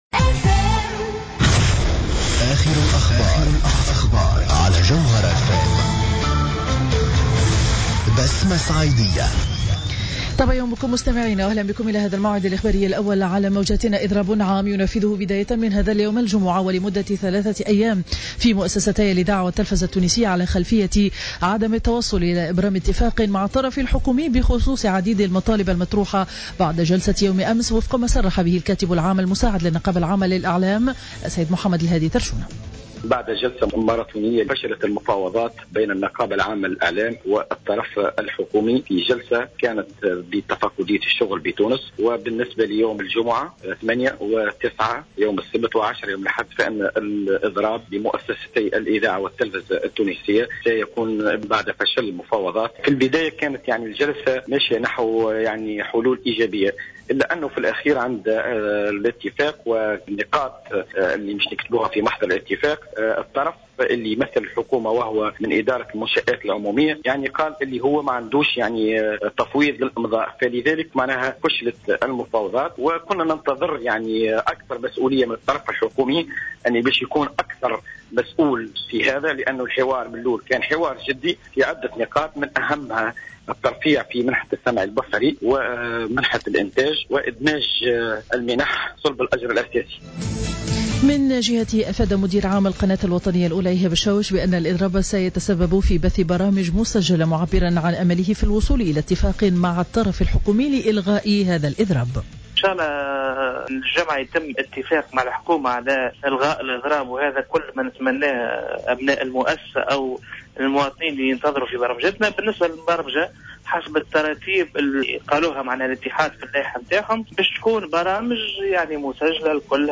نشرة أخبار السابعة صباحا ليوم الجمعة 8 ماي 2015